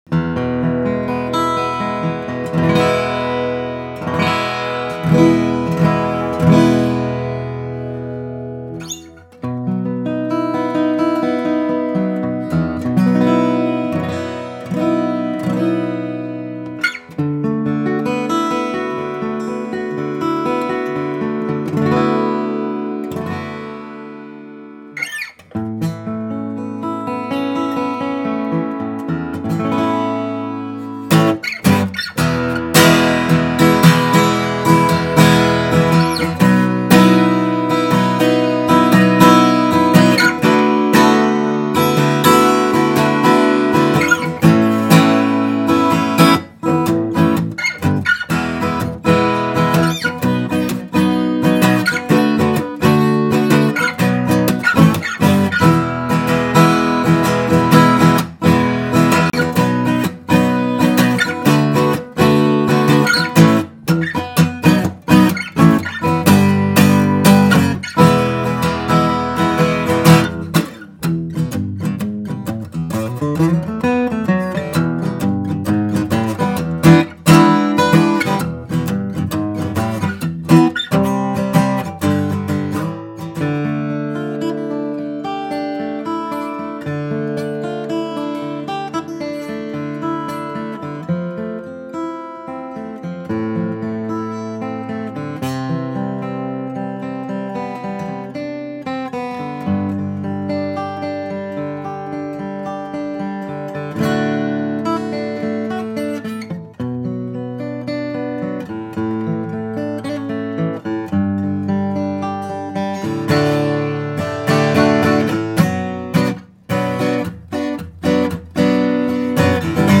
Feels and sounds like a true vintage player’s guitar with years of wear, right out of the case.
Very well-balanced, deep bass tones combined with crispy and solid mids.
Rarely have I heard these fat and sweet tones coming from the upper regions.